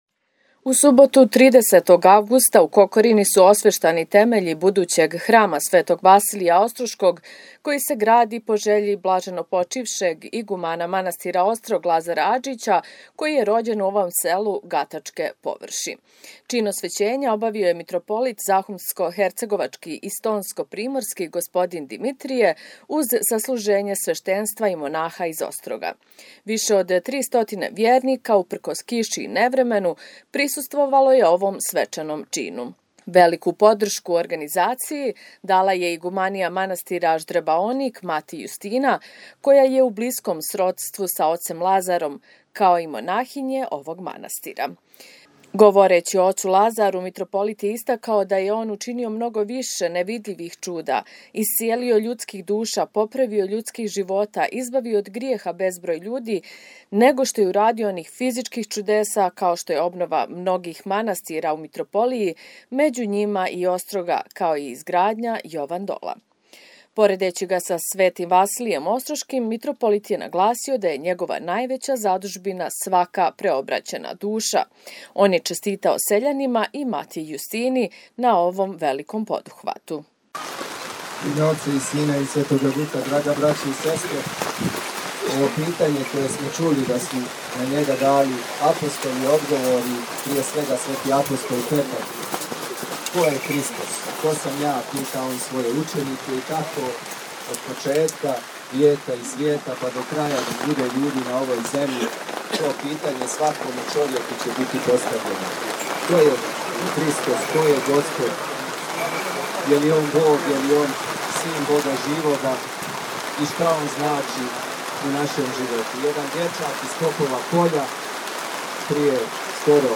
Čin osvećenja obavio je mitropolit zahumsko-hercegovački i stonsko-primorski gospodin Dimitrije uz sasluženje sveštenstva i monaha iz Ostroga. Više od tri stotine vjernika, uprkos kiši i nevremenu, prisustvovalo je ovom svečanom činu.